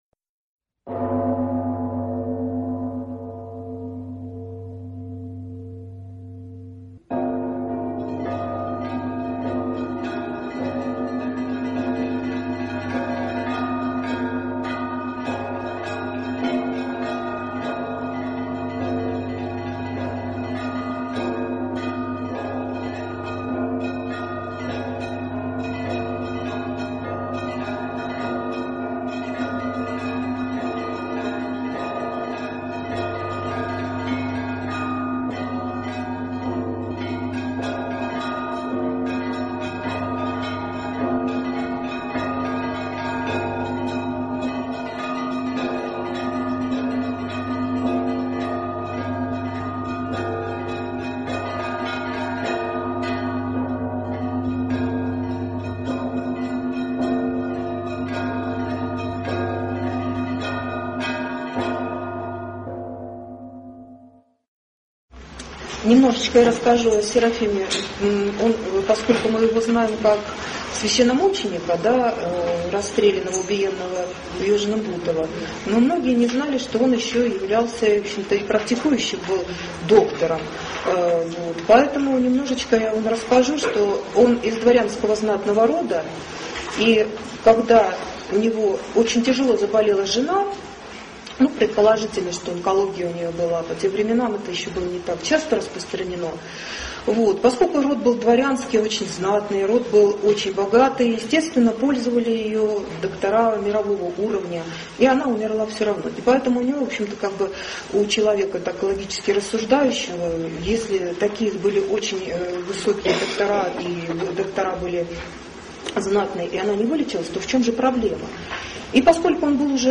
Аудиокнига Здоровье по Чичагову | Библиотека аудиокниг
Прослушать и бесплатно скачать фрагмент аудиокниги